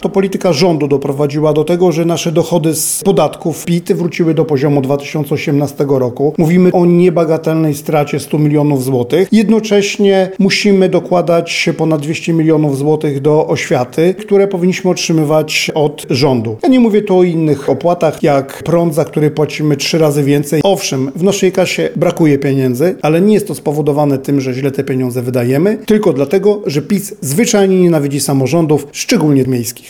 O odniesienie się do tych zarzutów poprosiliśmy prezydenta miasta Radosława Witkowskiego: